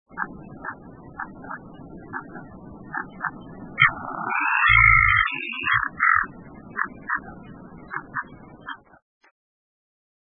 2126e「鳥の鳴声」
〔ライチョウ〕ゴアー，ゴアー／本州中部の高山に棲息，留鳥，35p